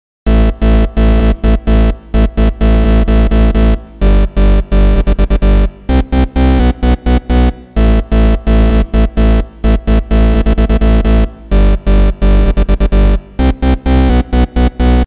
电房合成器1
描述：电音屋/128 bpm/fl studio/3xosc
Tag: 128 bpm House Loops Synth Loops 2.53 MB wav Key : Unknown